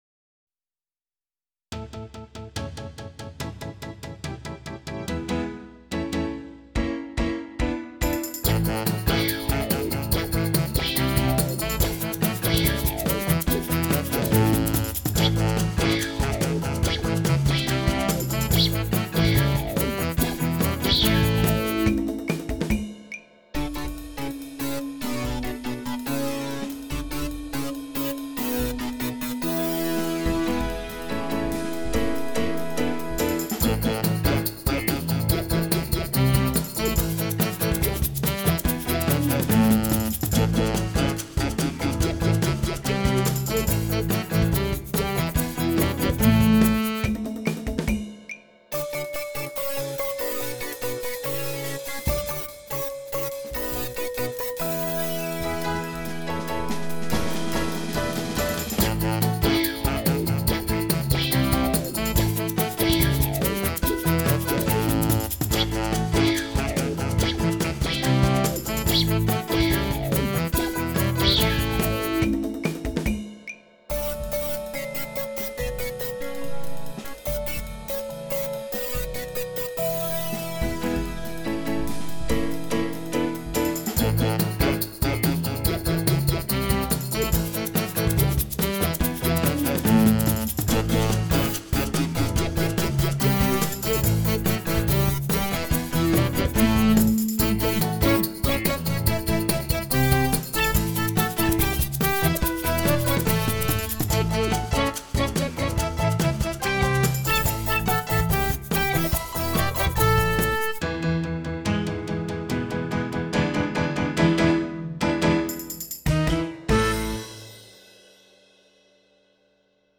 Download backing track